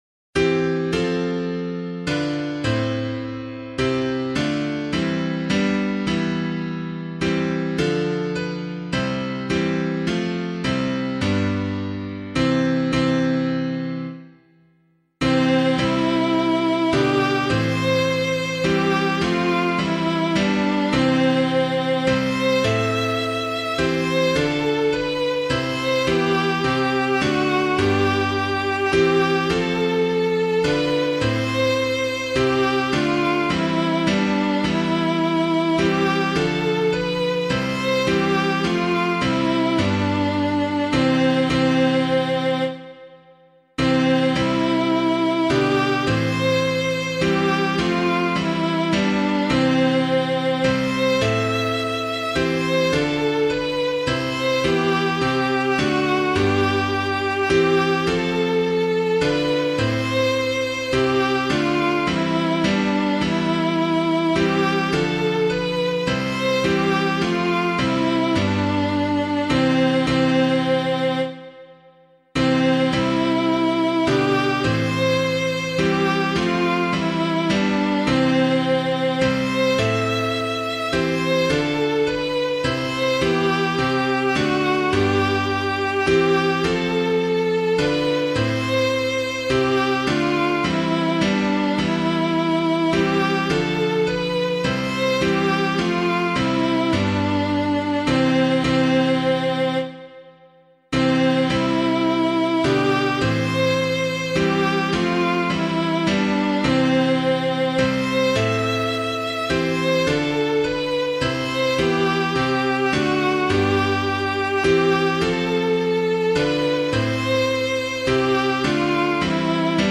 piano
Now Let Us from This Table Rise [Kaan - DEUS TUORUM MILITUM] - piano.mp3